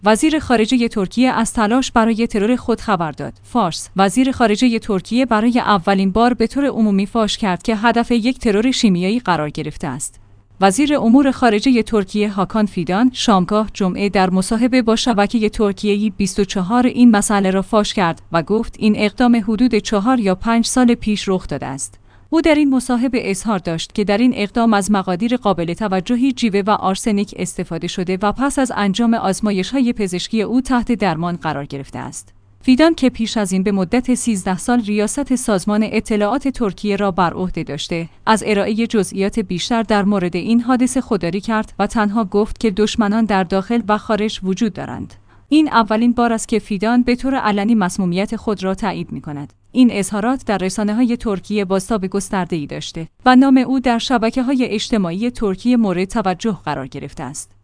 وزیر امور خارجه ترکیه «هاکان فیدان» شامگاه جمعه در مصاحبه‌ با شبکه ترکیه‌ای «24» این مسئله را فاش کرد و گفت این اقدام حدود 4 یا 5 سال پیش رخ داده است.